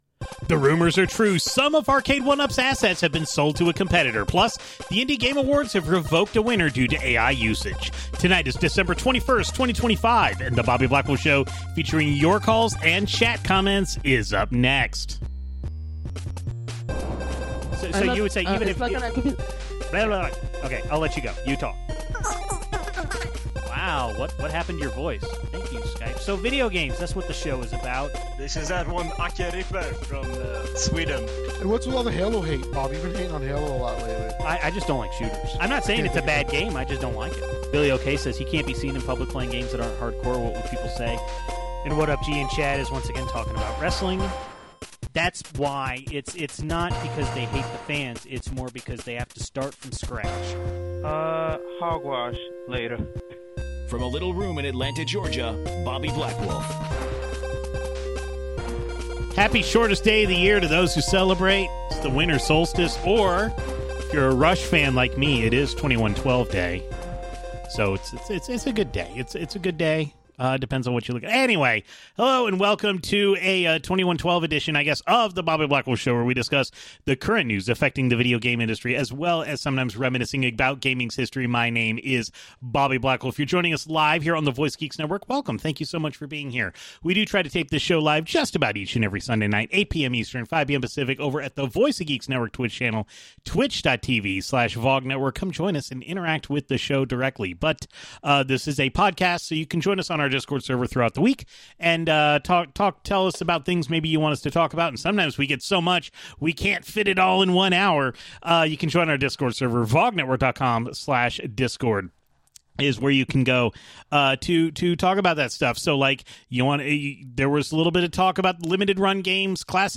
The Video Game History Foundation has released a bunch of ROMs of the 90's era Sega Channel, so you can relive the 90's goodness on your own emulator. Some of Arcade1up's assets have been sold to competitor Basic Fun! who entered the home arcade business earlier this year. The Indie Game Awards have revoked Clair Obscur: Expedition 33's awards due to the use of generative AI in development. Then we take a call about generative AI usage in the world.